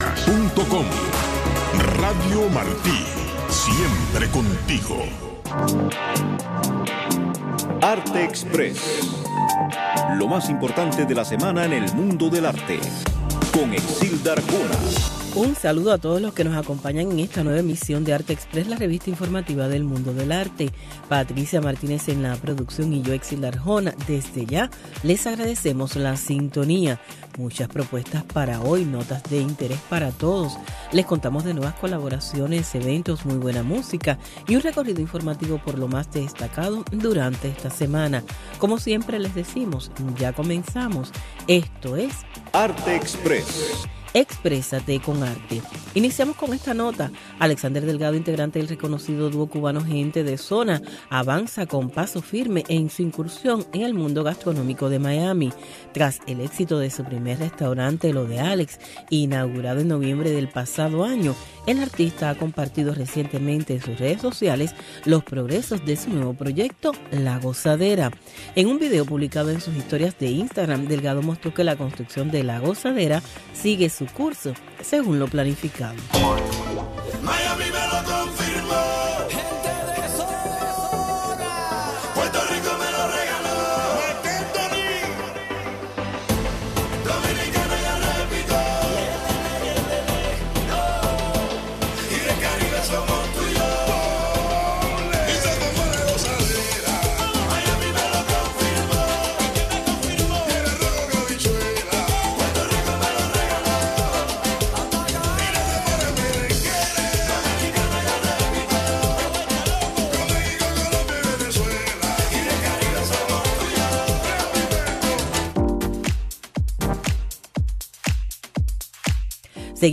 Revista informativa con noticias, eventos, blogs cubanos, efemérides, música y un resumen de lo más importante de la semana en el mundo del arte.